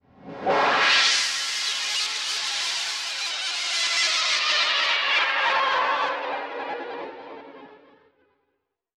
Index of /90_sSampleCDs/Spectrasonics - Bizarre Guitar/Partition H/07 SCRAPE SW